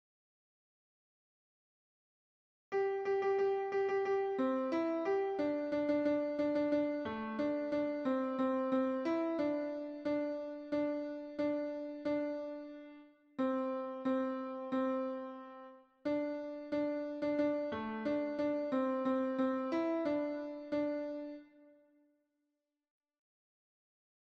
Basse 2